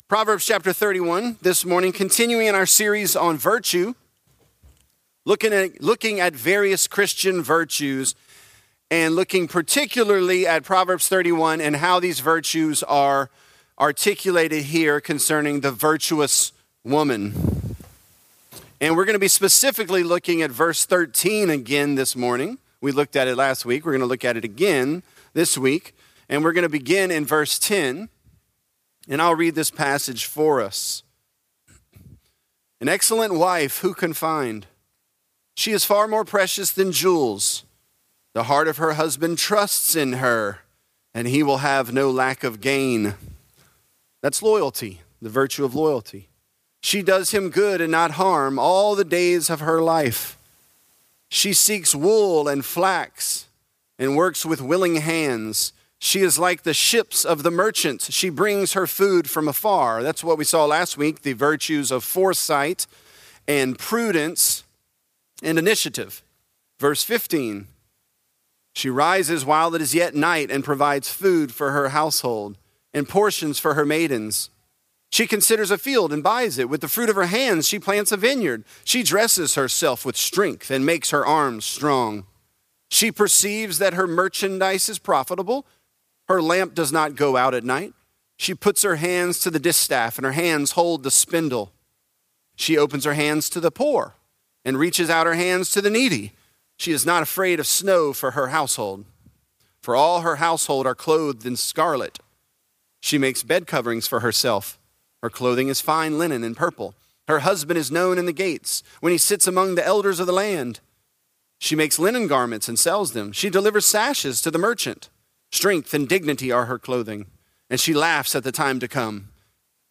This is a part of our sermon series, "Virtuous."